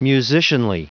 Prononciation du mot musicianly en anglais (fichier audio)
Prononciation du mot : musicianly